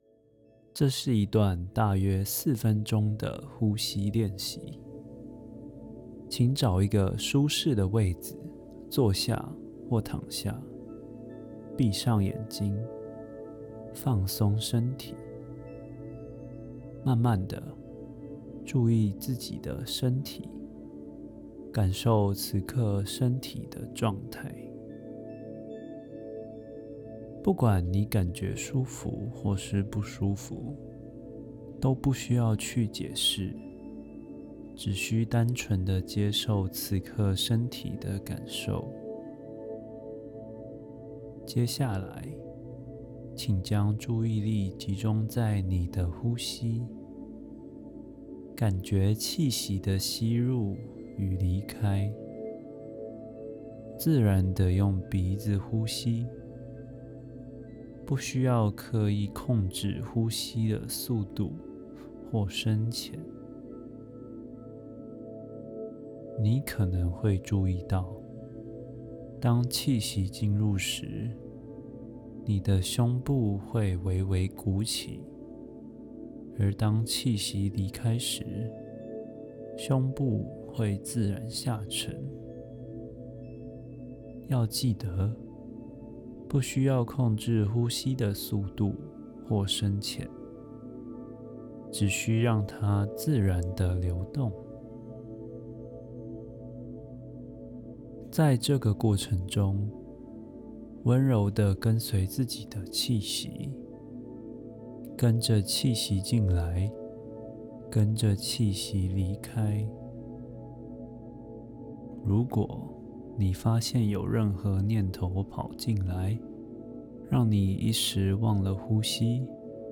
meditation_v2.mp3